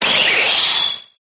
dracozolt_ambient.ogg